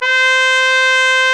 Index of /90_sSampleCDs/Club-50 - Foundations Roland/BRS_xTrumpets 1/BRS_xTrumpets 1